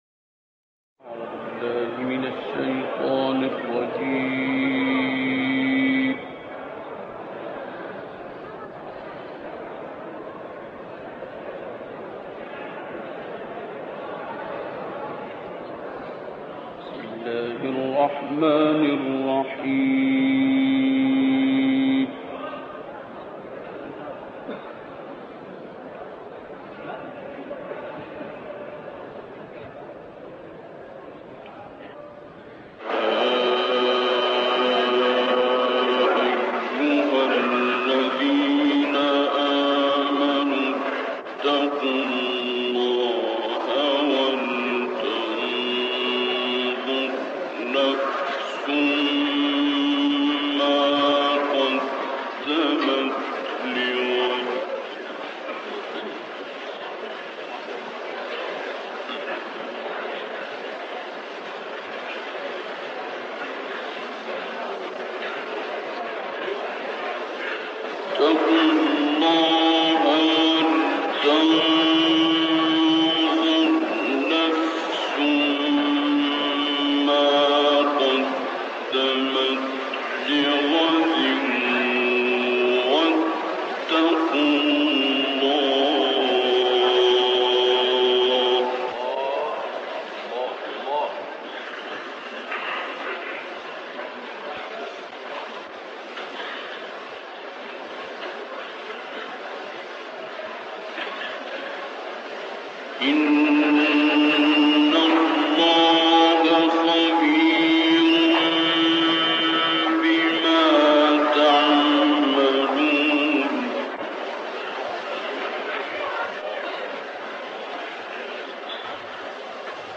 تلاوت سوره های حشر، تکویر و فجر استاد مرحوم عبدالباسط محمد عبدالصمد یکی از تلاوتهای شاهکار و ماندگار است که در سال 1956 میلادی در بغداد قرائت شد.